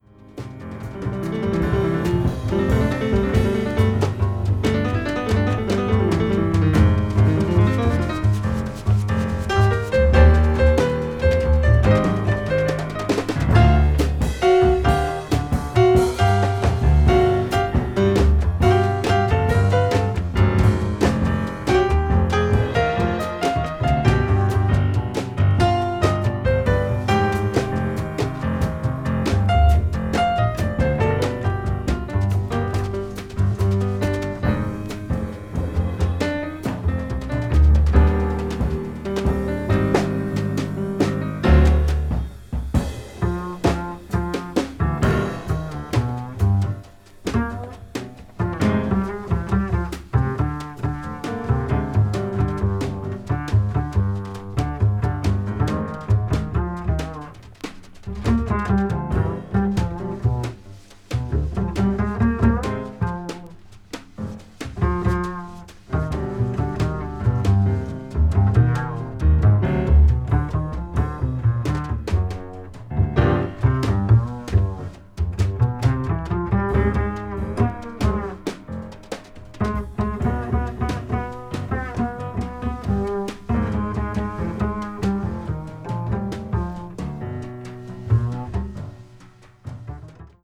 piano trio
contemporary jazz   modal jazz   piano trio   post bop